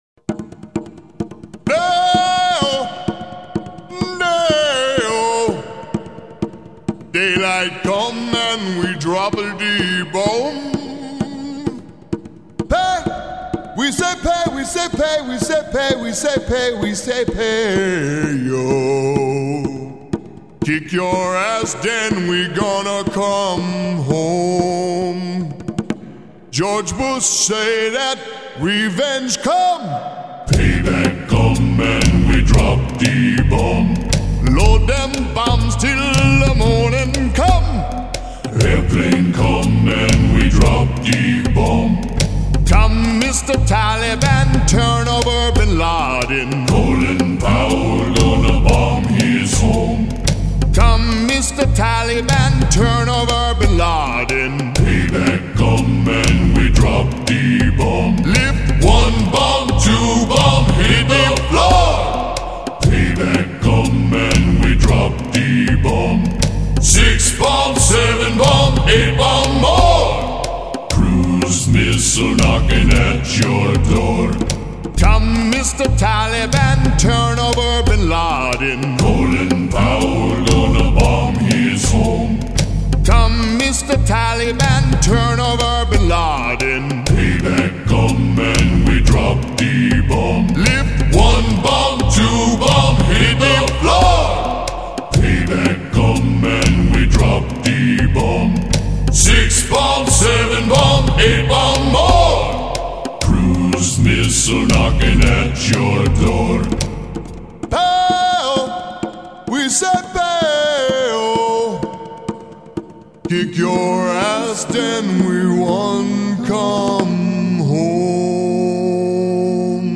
Taliban_Calypso.MP3